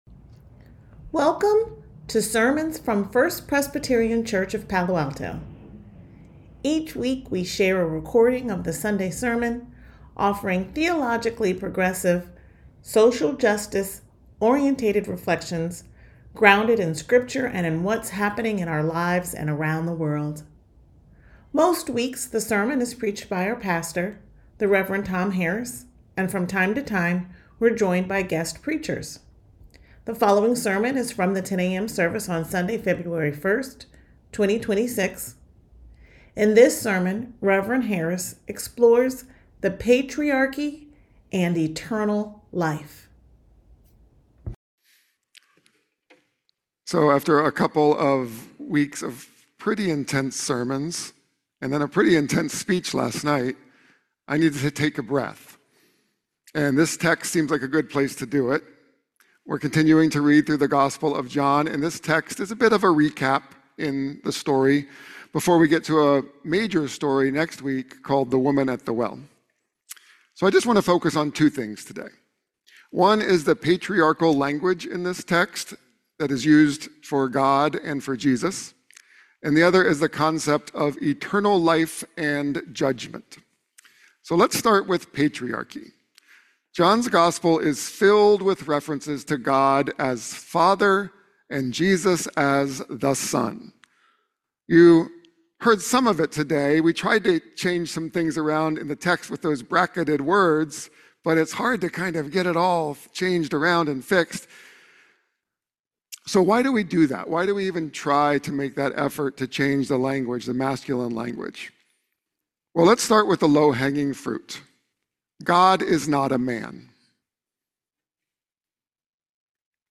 sermon2126b-1.mp3